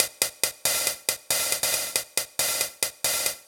Low Down Hats 138bpm.wav